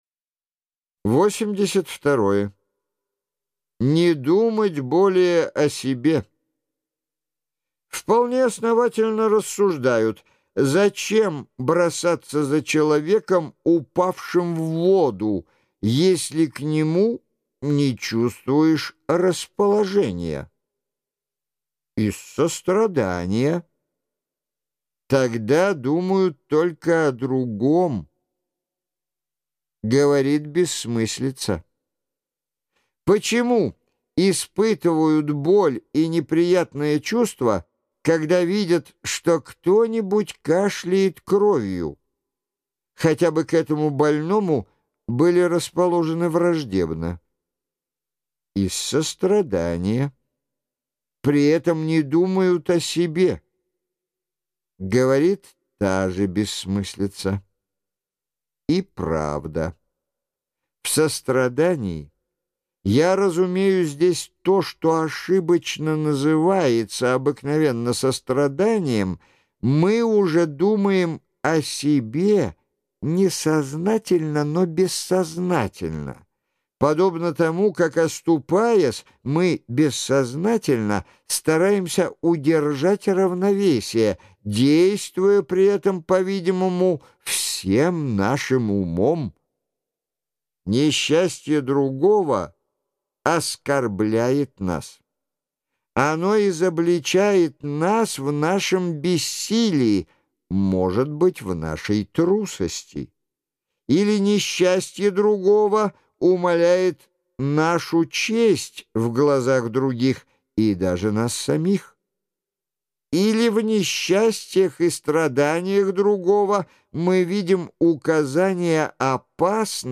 Жанр: Аудиокнига.